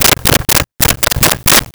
Telephone Dialed 01
Telephone Dialed 01.wav